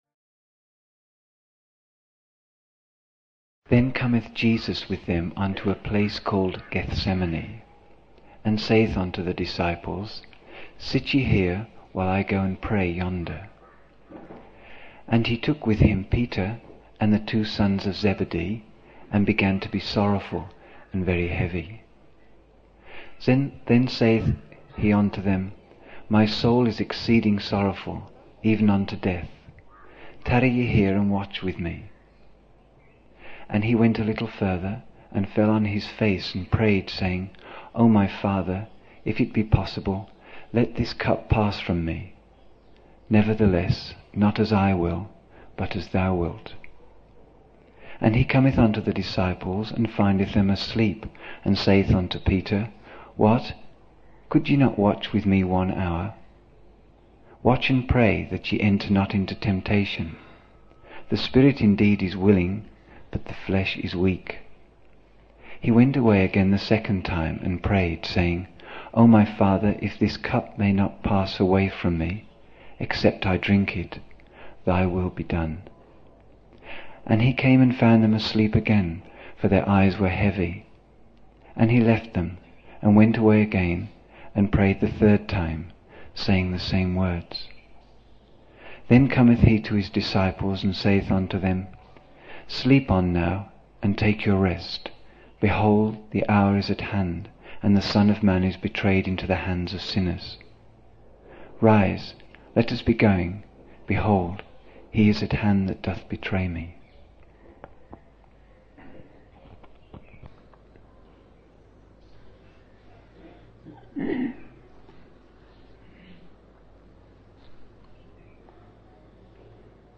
25 October 1975 morning in Buddha Hall, Poona, India